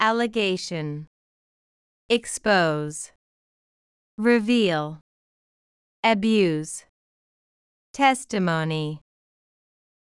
allegation /ˌæləˈɡeɪʃən/（名）申し立て、疑惑
expose /ɪkˈspoʊz/（動）さらす、暴露する
reveal /rɪˈviːl/（動）明らかにする、暴露する
abuse /əˈbjuːz/（動）虐待する
testimony /ˈtɛstəˌmoʊni/（名）証言、証拠